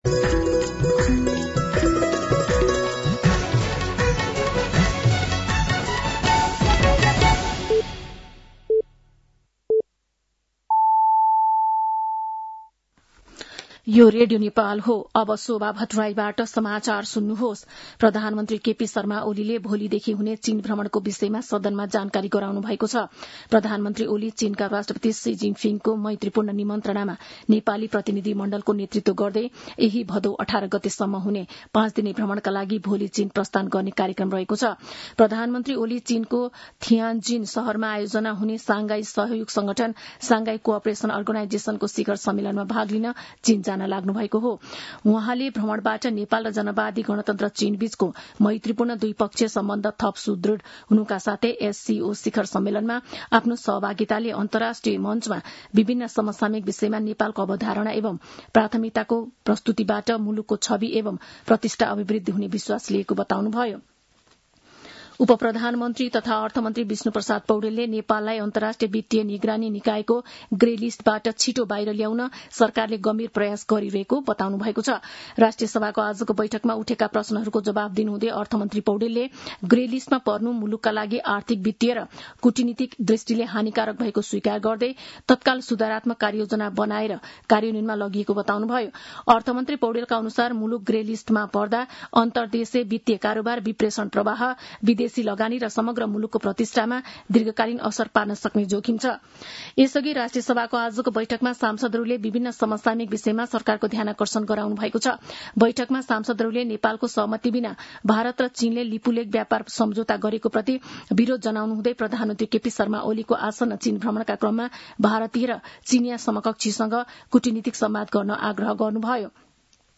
साँझ ५ बजेको नेपाली समाचार : १३ भदौ , २०८२